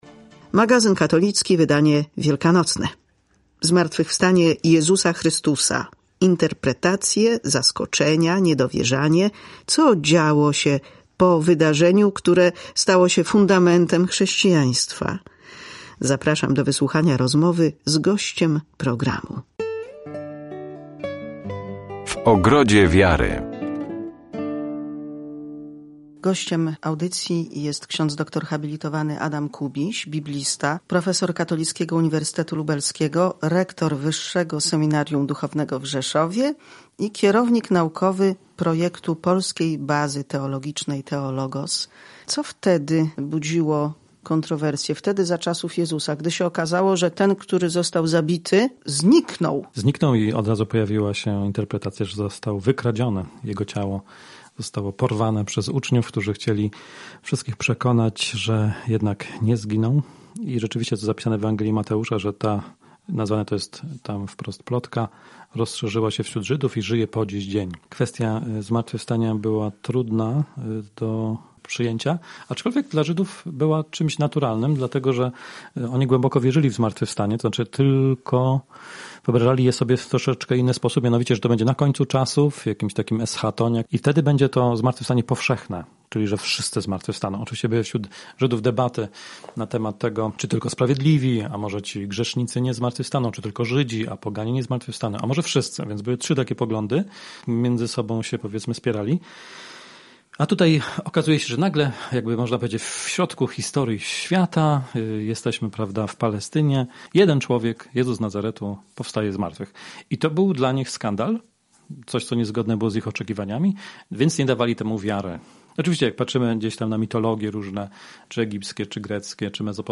W ogrodzie wiary • W magazynie katolickim rozmawiamy o zmartwychwstaniu. Za czasów Chrystusa nie brakowało domysłów, plotek, interpretacji.